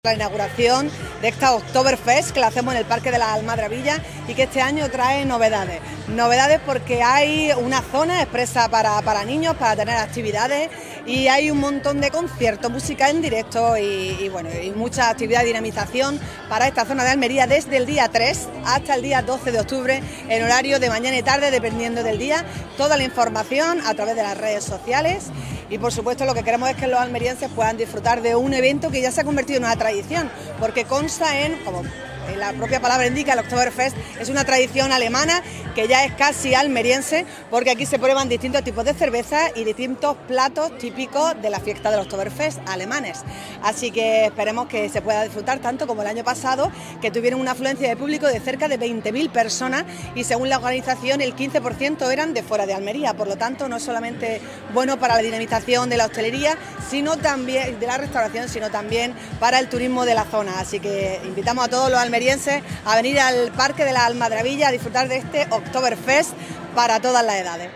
ALCALDESA-OKTOBERFEST.mp3